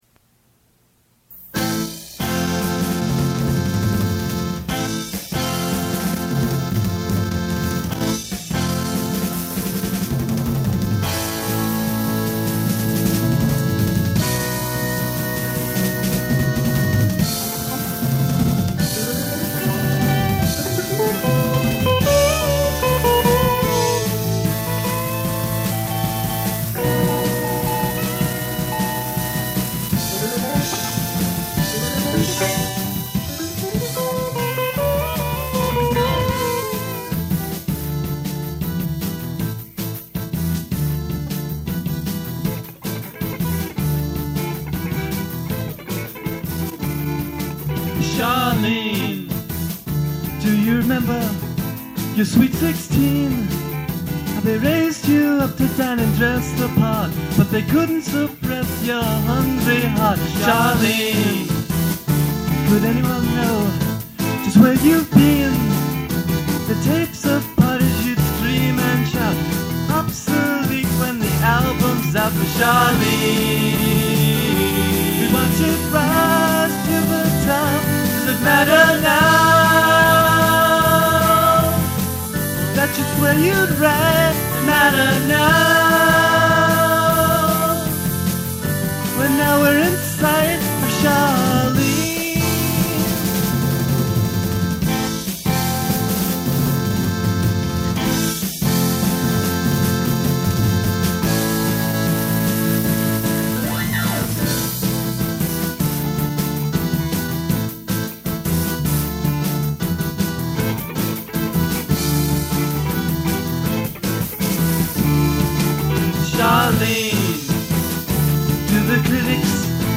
percussion, vocals
bass guitar
keyboards, vocals
guitar, vocals